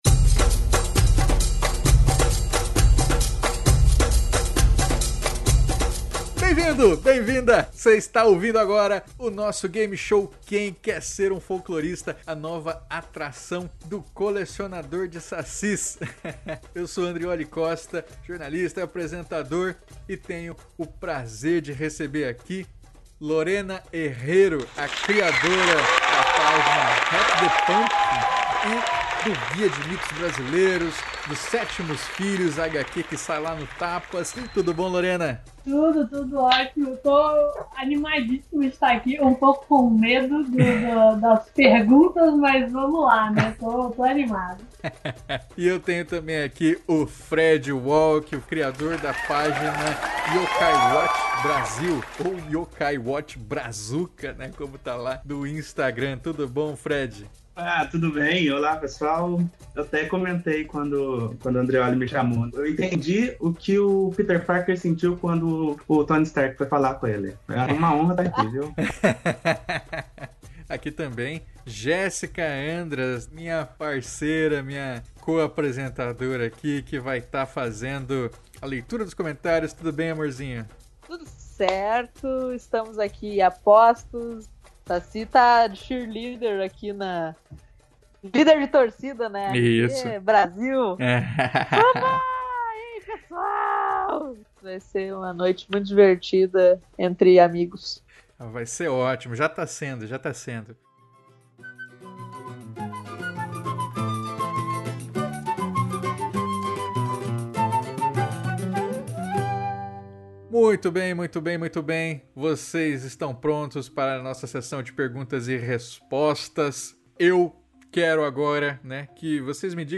Nesse gameshow de folclore vamos aprender brincando enquanto os participantes disputam seus conhecimentos sobre cultura popular na disputa pelo grande prêmio “Saci de Ouro”.